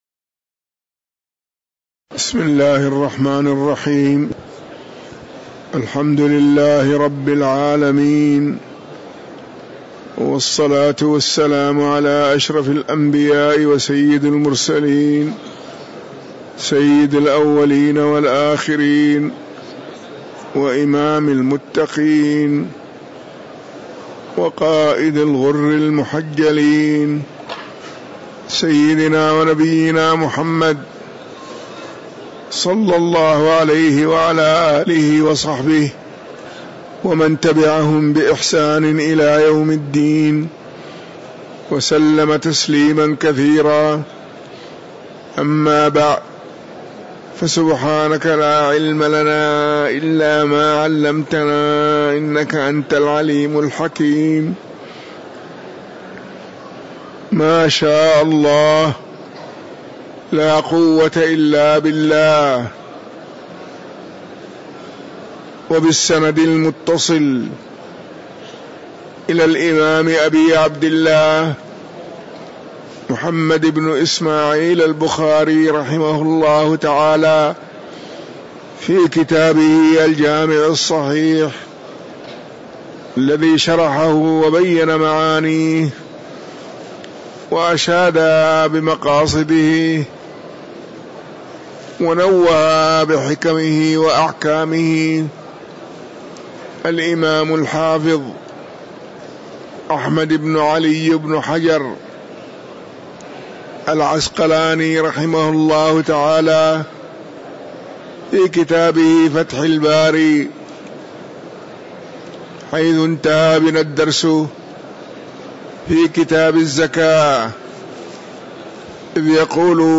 تاريخ النشر ٢٩ ربيع الأول ١٤٤٤ هـ المكان: المسجد النبوي الشيخ